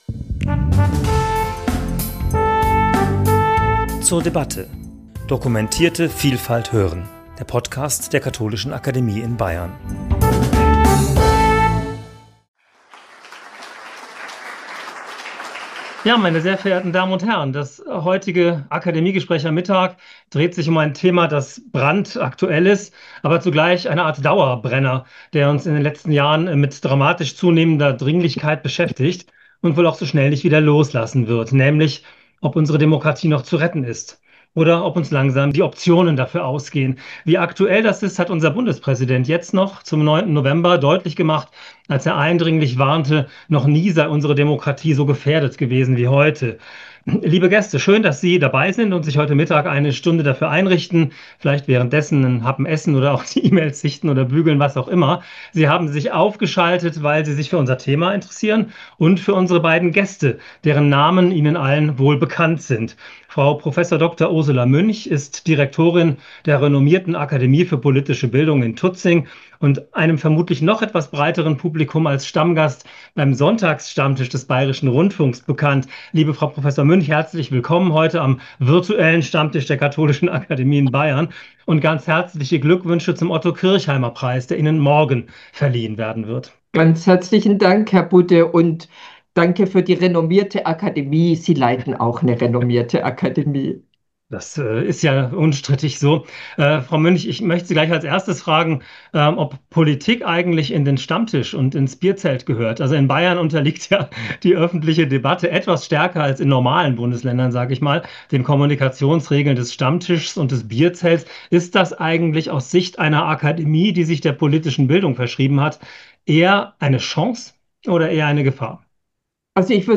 Gespräch zum Thema 'Optionen der demokratischen Mehrheit' ~ zur debatte Podcast